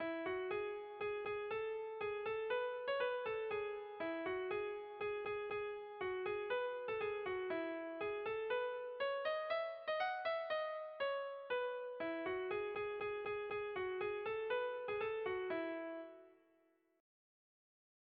Kontakizunezkoa
Zortziko txikia (hg) / Lau puntuko txikia (ip)
A-A2-B-A2